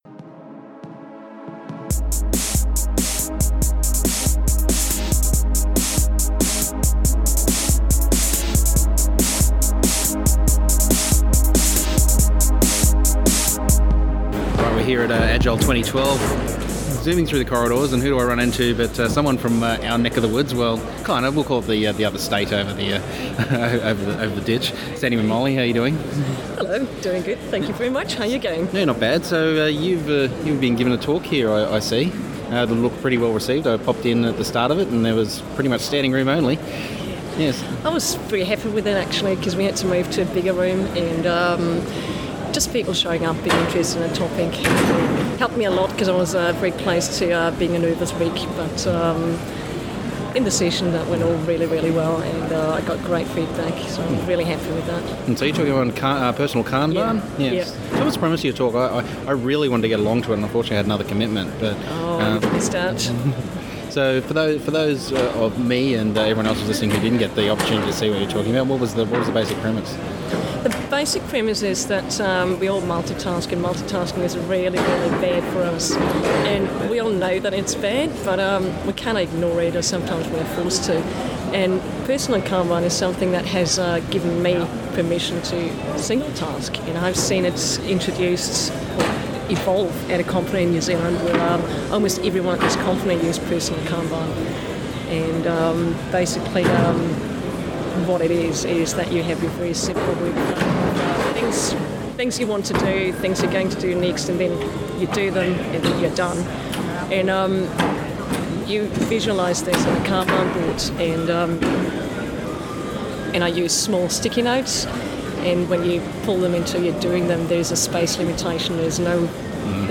In this previously lost and unreleased podcast from 2012 (we found it on a SD card that was thought to be lost forever)
at Agile 2012 in Dallas, Texas